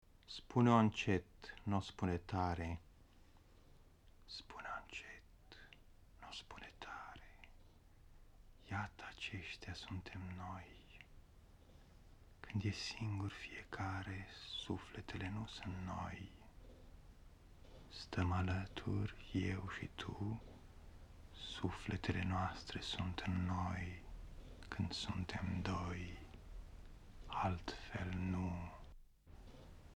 • poetry
• radio programs